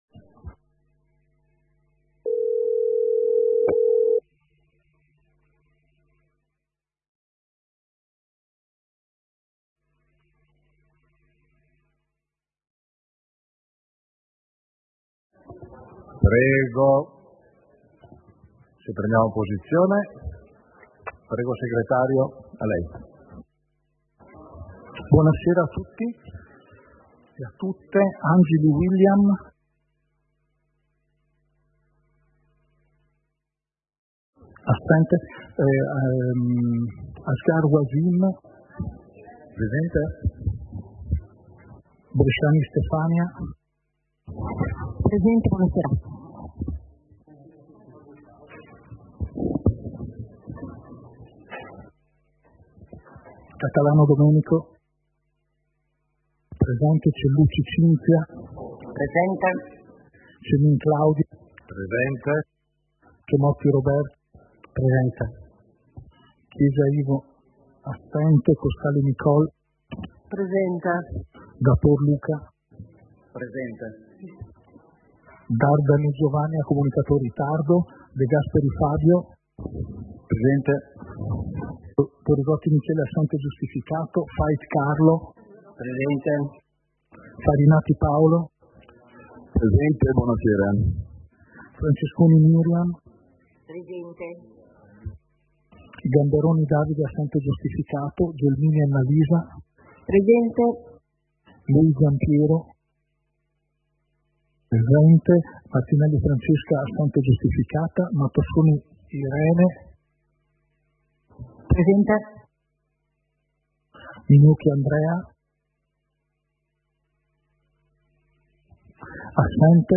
Seduta del consiglio comunale - 03.02.2026